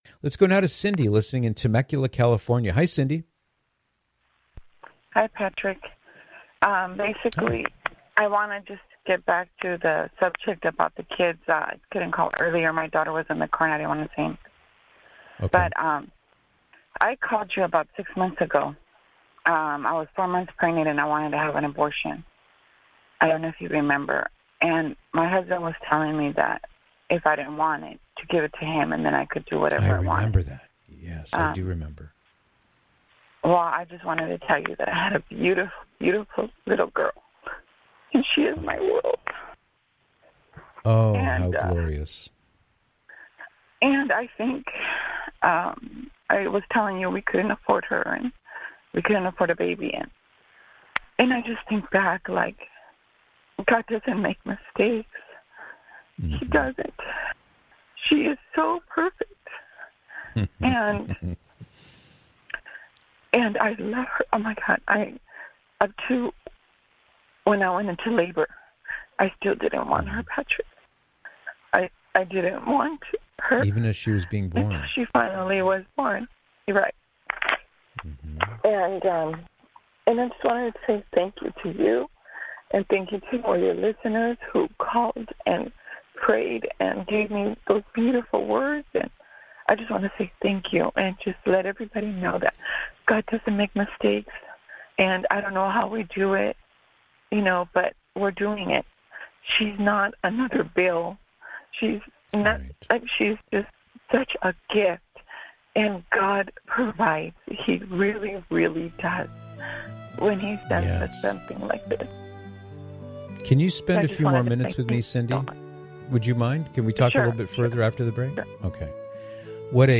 One of the wonderful things about live radio is the opportunity for our hosts to interact with listeners who call in to our shows.